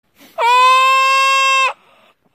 Сибирская кабарга издает крик один раз